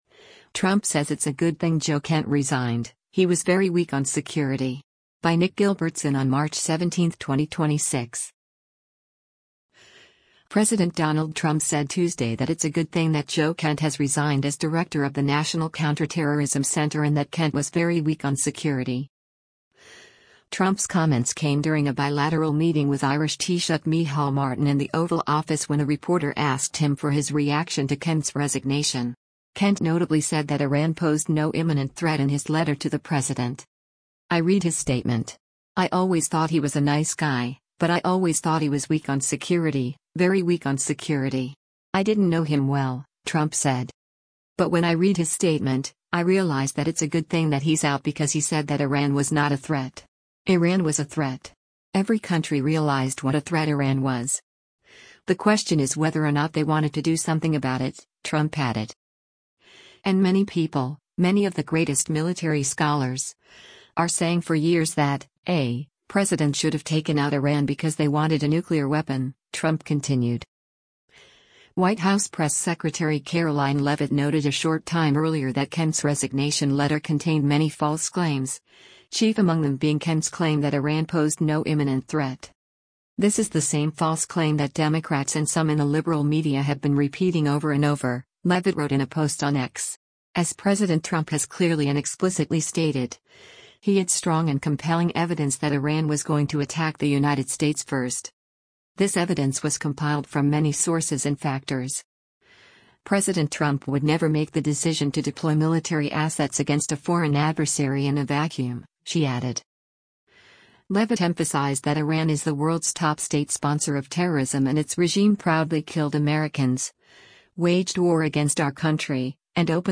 Trump’s comments came during a bilateral meeting with Irish Taoiseach Micheál Martin in the Oval Office when a reporter asked him for his reaction to Kent’s resignation.